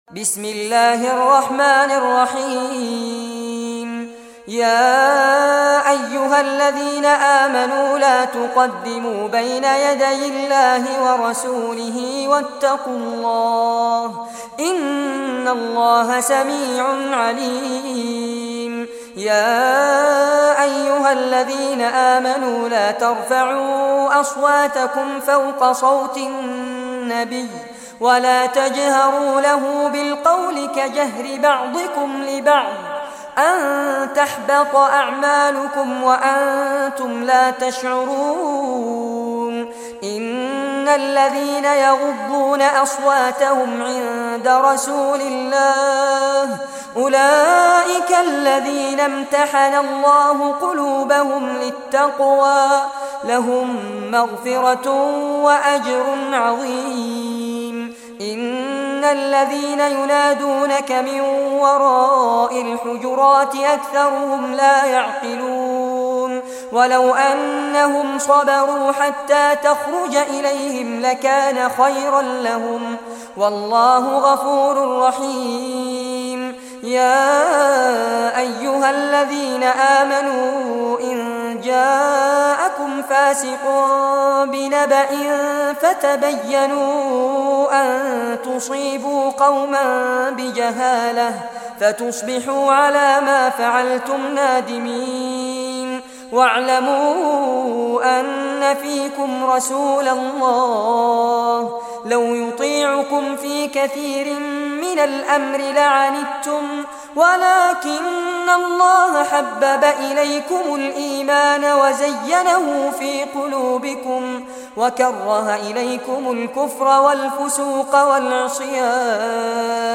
Surah al-Hujurat Recitation by Fares Abbad
Surah al-Hujurat, listen or play online mp3 tilawat / recitation in Arabic in the beautiful voice of Sheikh Fares Abbad.